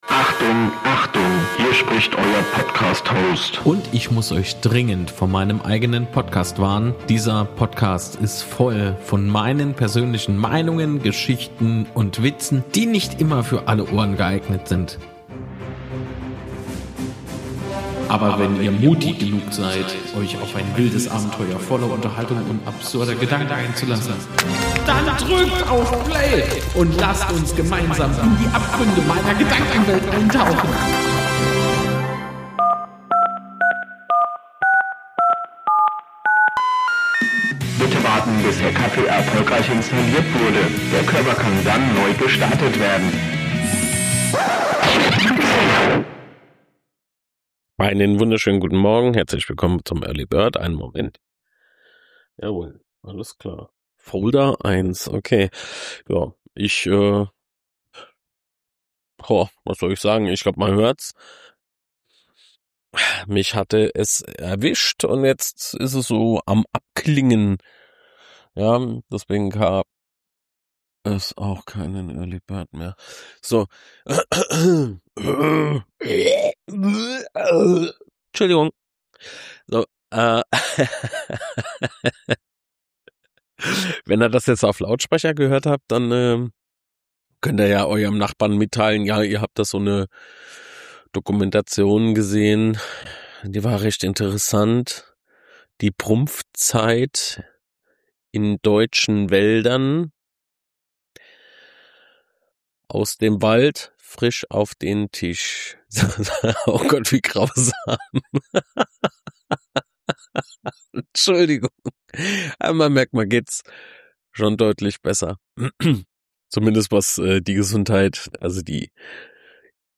P.s.: Diese Episode wurde durch Auphonic gefiltert, da starke Windgeräusche in einer kurzen Passage auftraten.